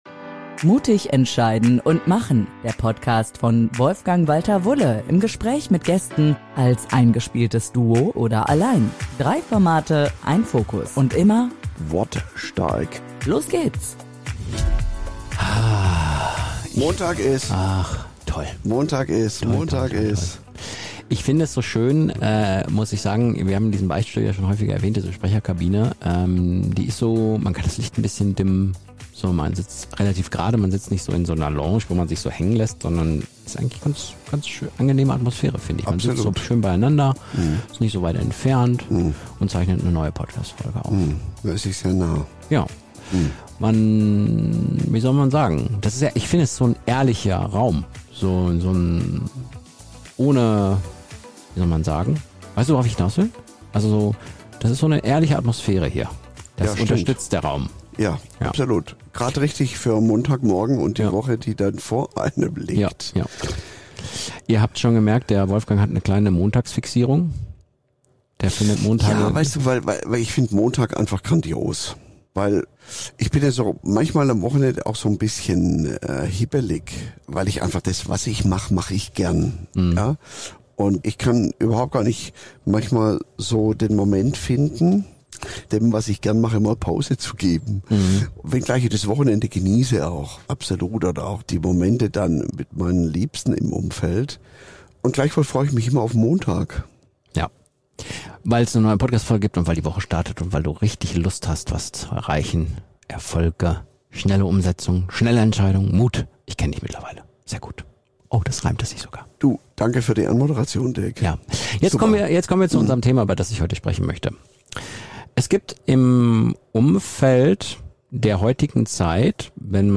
Wann sind wir wirklich wir selbst – und wann verlieren wir den Kontakt zu unserer inneren Haltung? Ein ehrlicher Schlagabtausch über Rollen, Selbstwahrnehmung und die Frage, warum echte Wirkung nur dort entsteht, wo wir uns selbst kennen.